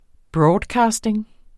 Udtale [ ˈbɹɒːdˌkɑːsdeŋ ]